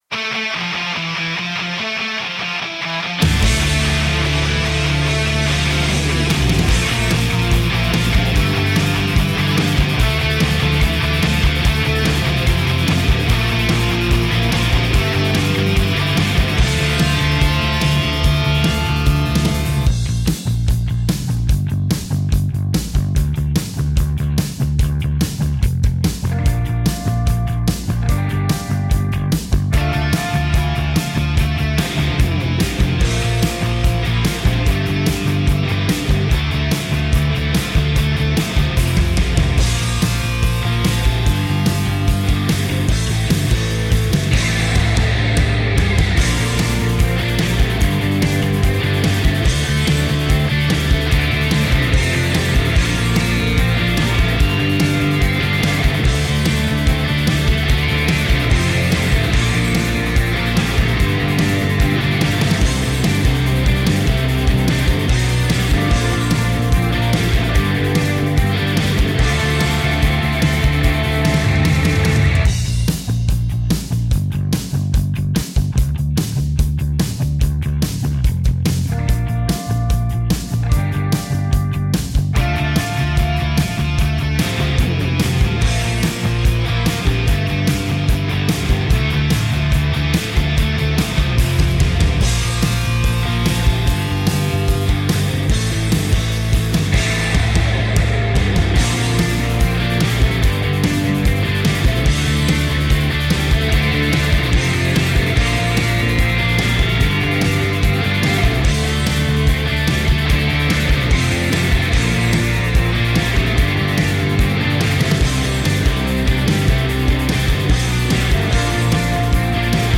Apocalyptic rock.
Tagged as: Hard Rock, Metal, Instrumental, Intense Metal